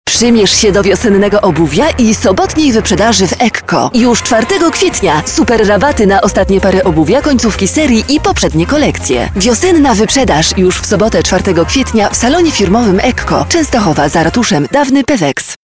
Professionelle polnische Sprecherin für TV / Rundfunk / Industrie.
Sprechprobe: eLearning (Muttersprache):